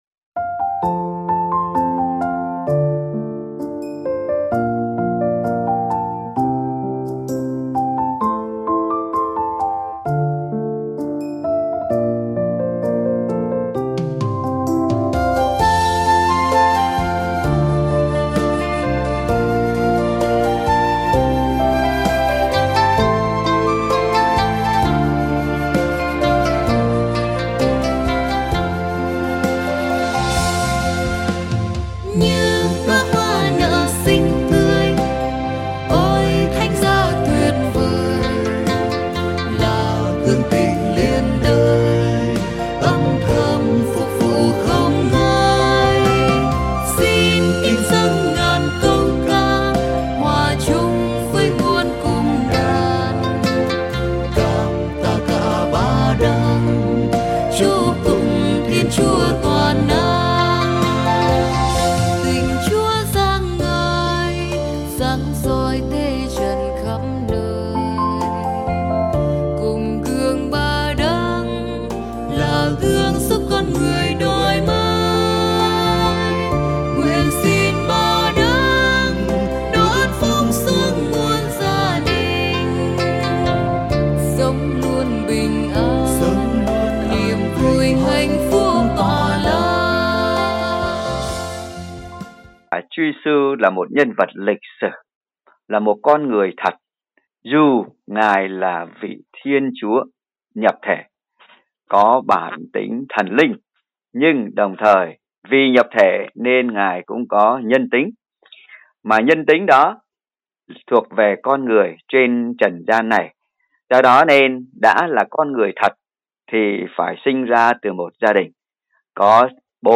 ĐTCPhanxico-HuanTuTruyenTinCNLeThanhGia.mp3